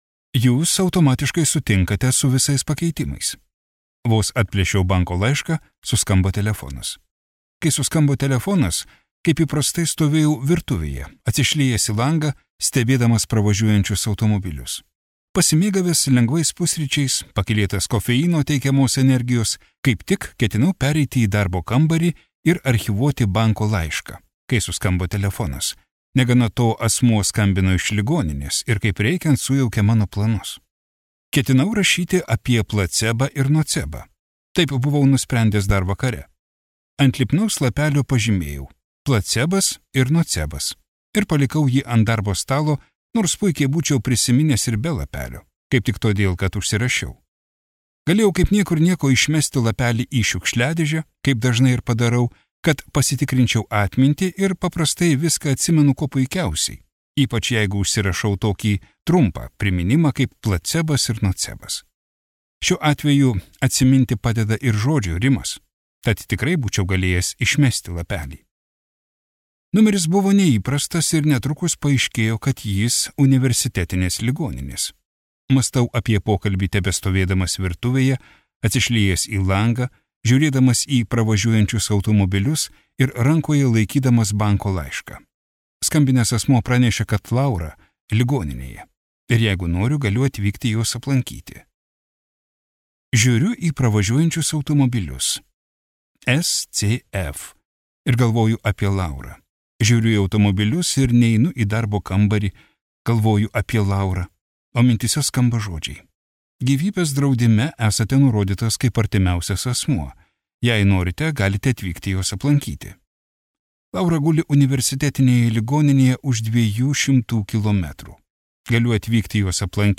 Suomių rašytojo J. P. Laitineno audioknyga „Fiktyvus“ apie filosofą ir gyvenimo vingius leidusius patikrinti savo išvestą teoriją.